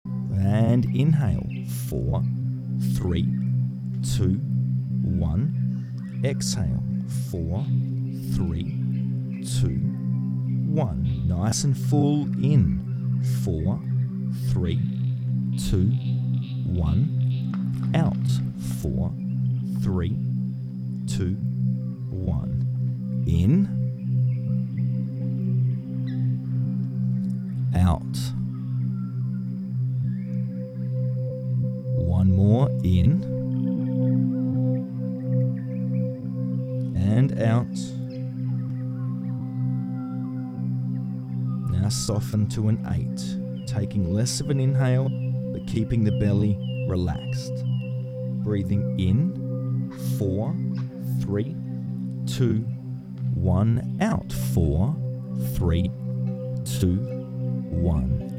Each track weaves together a bespoke breathwork technique and a curated soundscape, inspired by one of the four elements; Air, Water, Earth & Fire, to deeply relax you.
• The guided breathwork on this album is suitable for anyone in good health